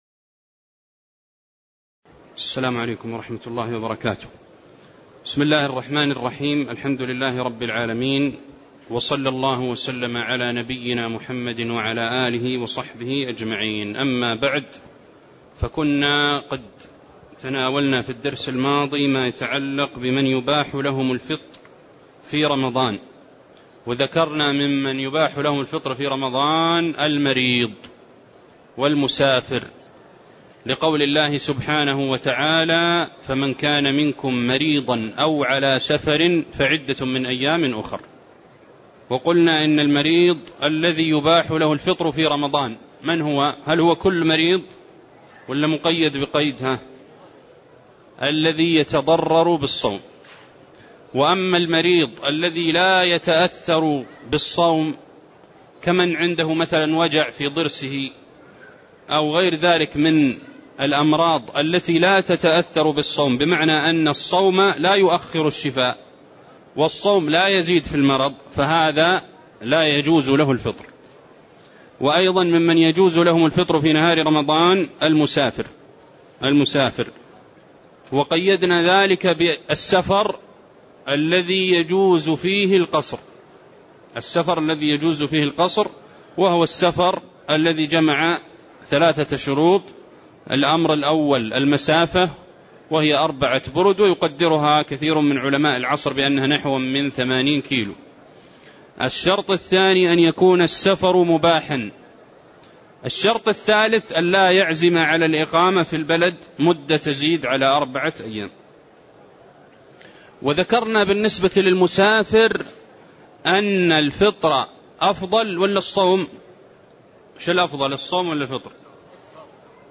تاريخ النشر ٢٦ شعبان ١٤٣٧ هـ المكان: المسجد النبوي الشيخ